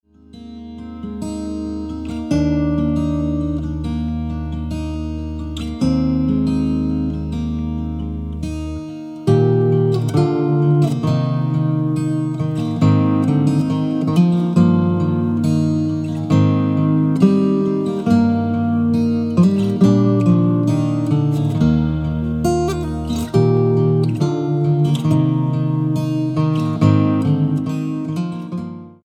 STYLE: Pop
instrumental renditions of carols
a pleasant and relaxing collection